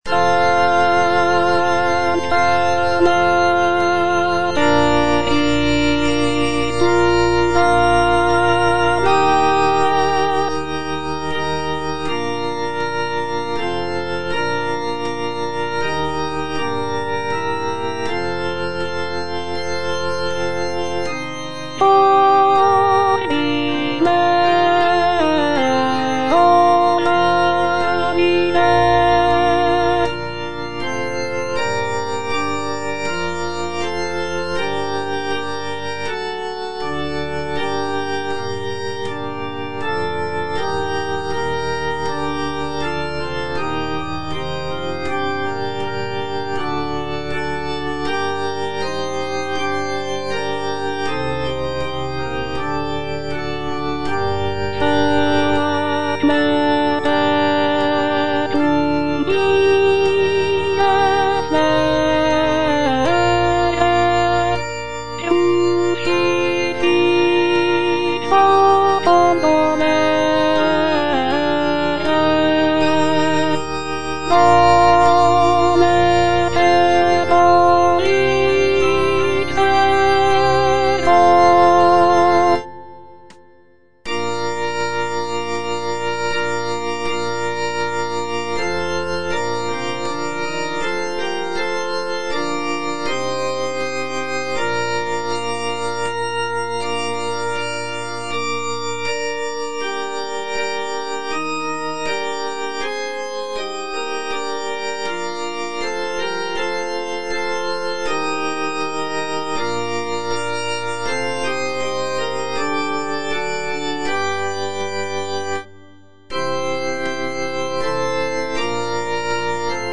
G.P. DA PALESTRINA - STABAT MATER Sancta Mater, istud agas (alto II) (Voice with metronome) Ads stop: auto-stop
sacred choral work